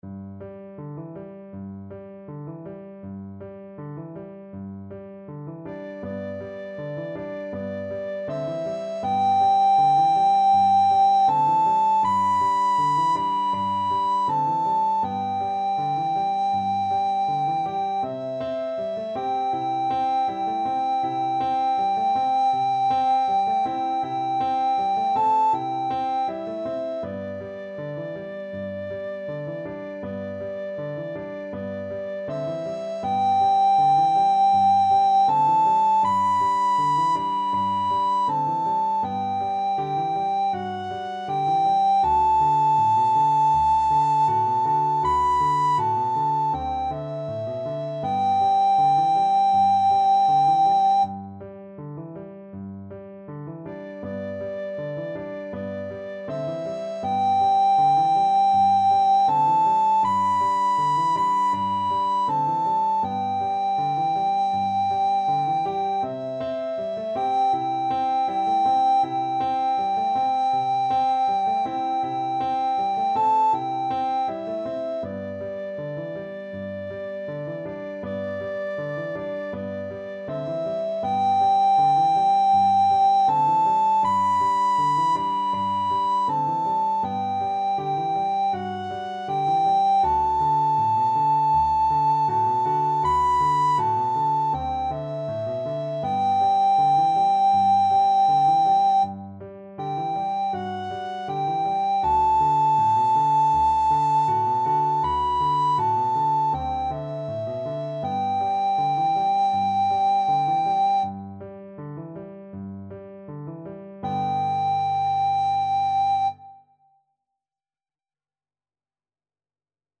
Free Sheet music for Soprano (Descant) Recorder
4/4 (View more 4/4 Music)
G major (Sounding Pitch) (View more G major Music for Recorder )
Molto Allegro = c.160 =160 (View more music marked Allegro)
Traditional (View more Traditional Recorder Music)
jazz (View more jazz Recorder Music)